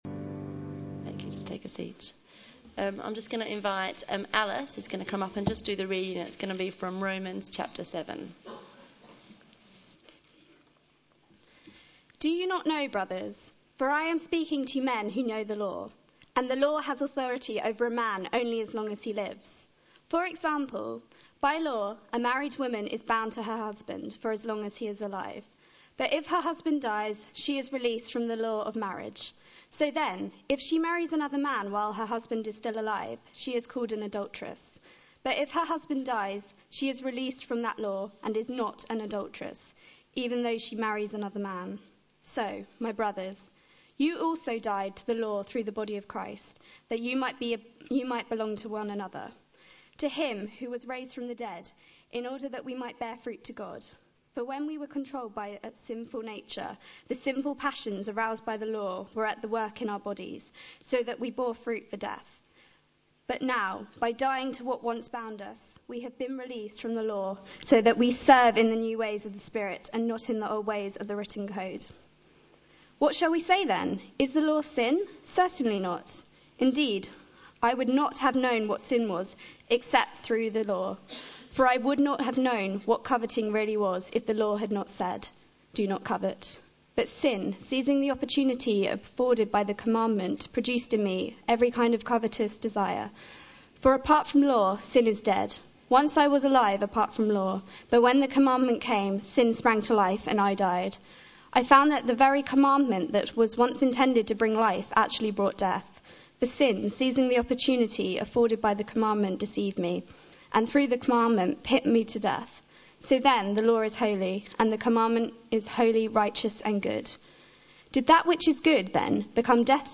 Media Library Media for Sunday Service on Sun 20th Nov 2011 10:00 Speaker
Romans 7.1-26 Series: The Letter to the Romans Theme: I don't want to do wrong, but... Sermon To find a past sermon use the search bar below You can search by date, sermon topic, sermon series (e.g. Book of the Bible series), bible passage or name of preacher (full or partial) .